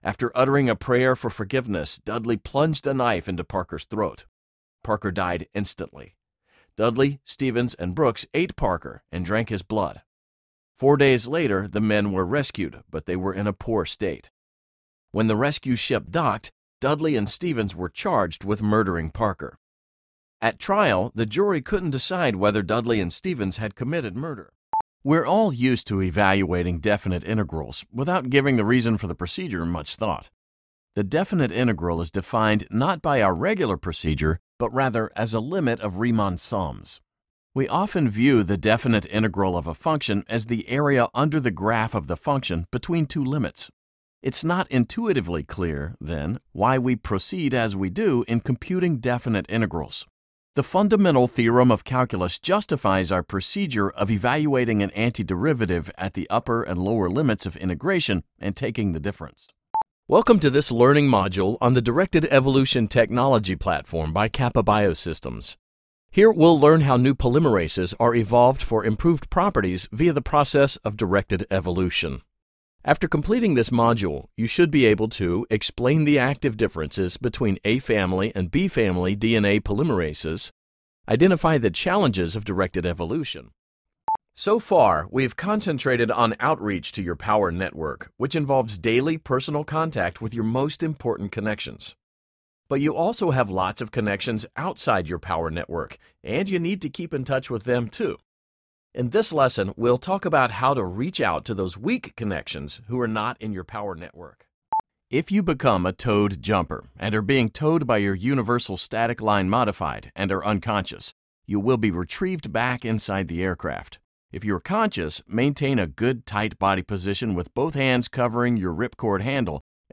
Rich, baritone voice, perfect for elearning, commercials, and more! Files usually delivered within 24 from professional studio.
mid-atlantic
Sprechprobe: eLearning (Muttersprache):
ISDN-equipped American voice actor.